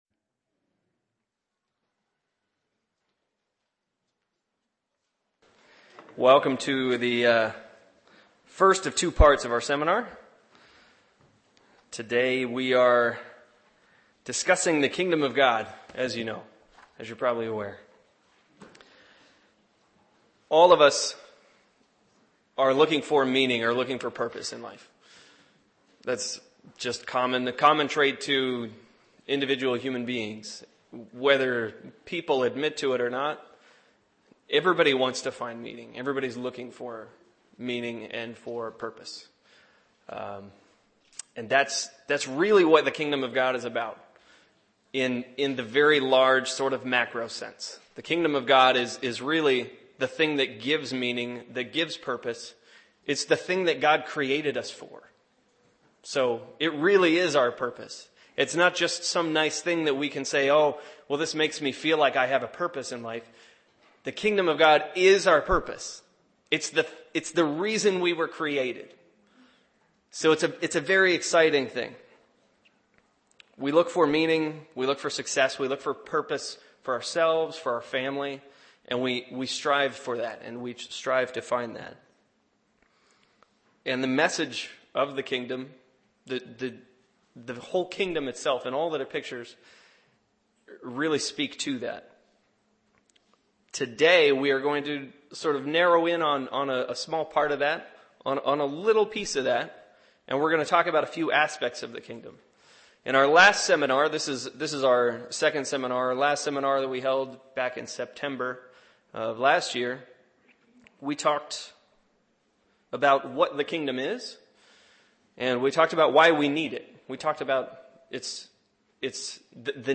What did Jesus mean "the time is fulfilled"? God is very involved in timing, on the macro and micro levels. Learn more in this Kingdom of God seminar.